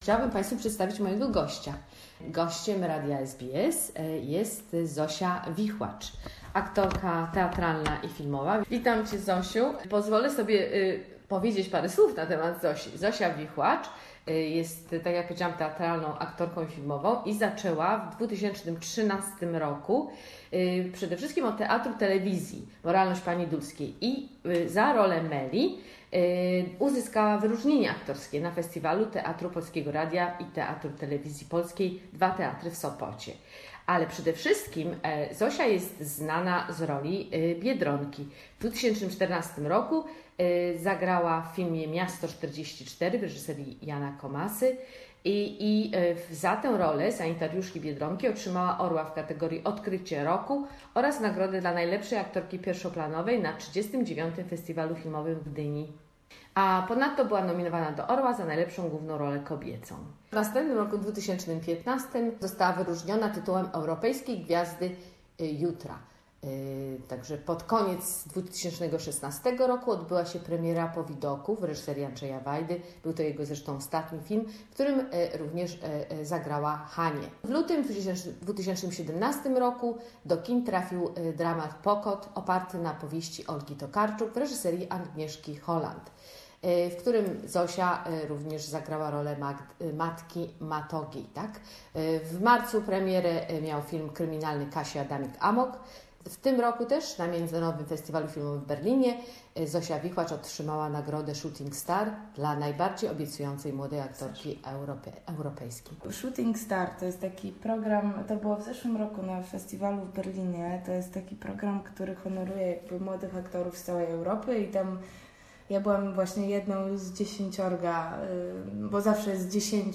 A conversation with the European Shooting Stars (Berlin 2017) and the lead of the war film Warsaw 44 on her new Netflix role and hers mentors , Andrzej Wajda and Agnieszka Holland.